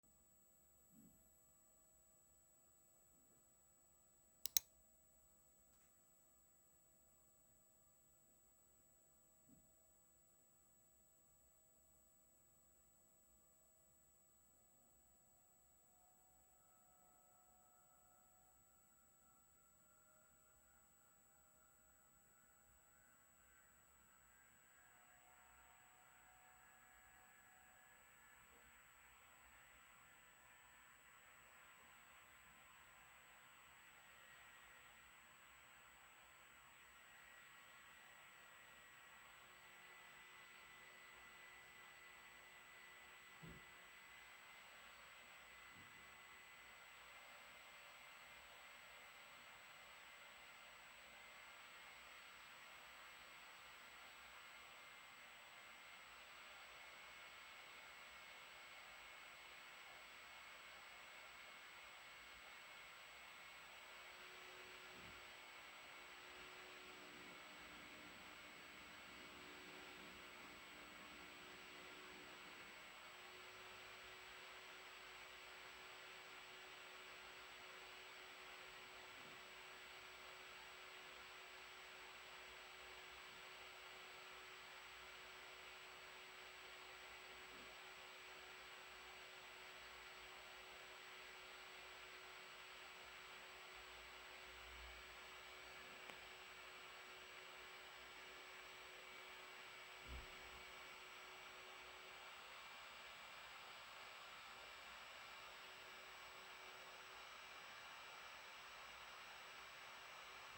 Der Leistungsmodus wird eigentlich nur viel schneller laut, vor allem im Leerlauf ist der Lüfter unnötig aktiv.
Audio-Impressionen des Kühlsystems
Die Aufnahme erfolgte mit 40 Zentimetern Abstand zur Gehäusefront aus dem Leerlauf in einen CPU-Volllast-Benchmark.